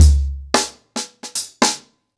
HarlemBrother-110BPM.30.wav